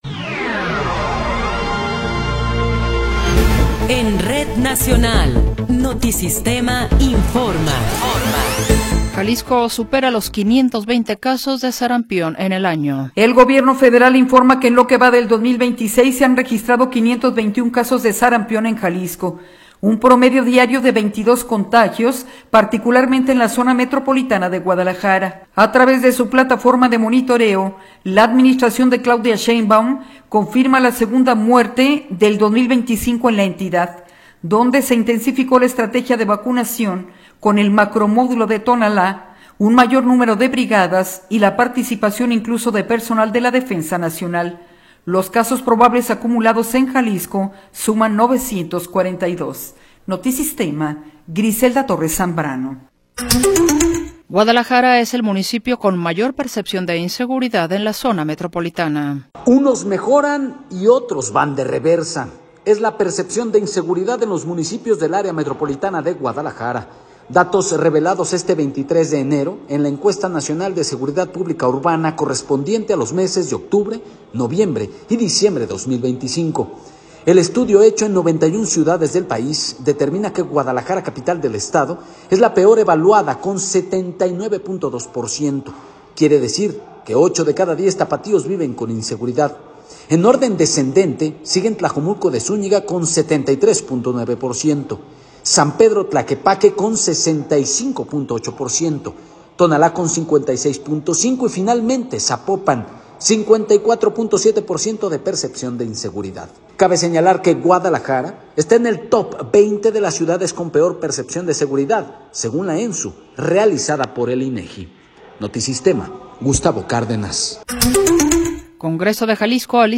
Noticiero 16 hrs. – 23 de Enero de 2026
Resumen informativo Notisistema, la mejor y más completa información cada hora en la hora.